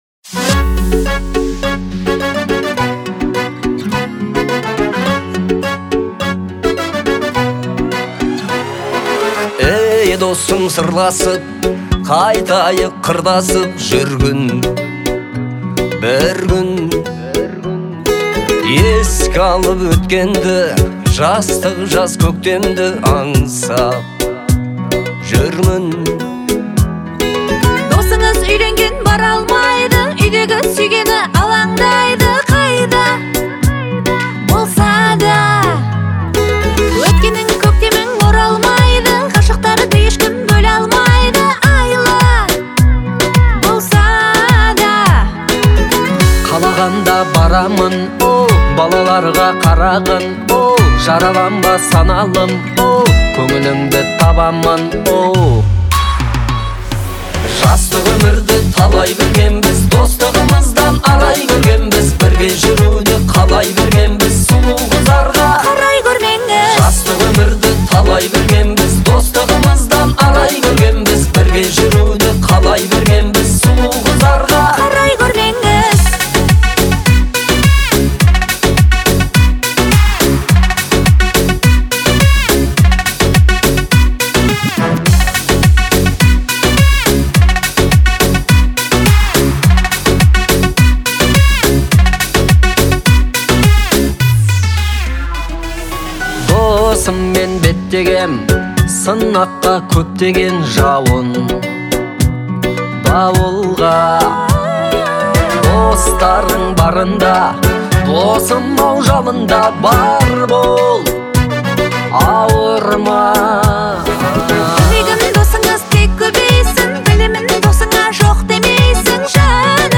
это трек в жанре поп